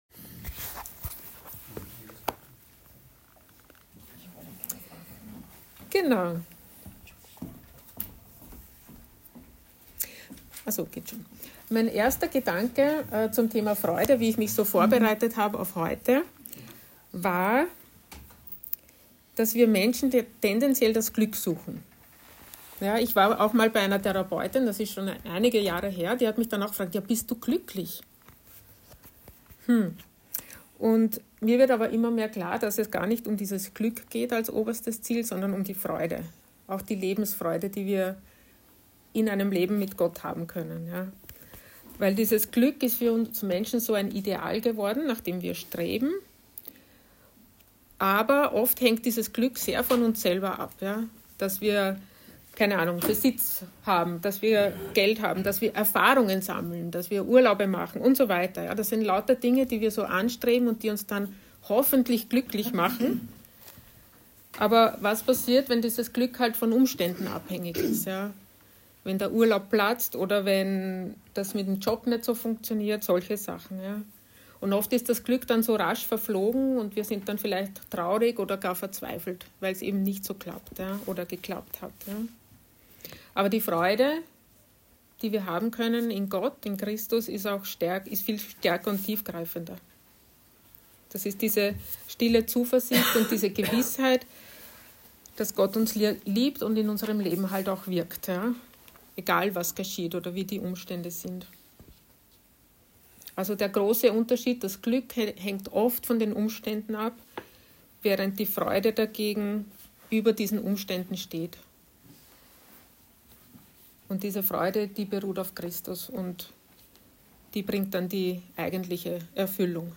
Predigt-Visionsgottesdienst.mp3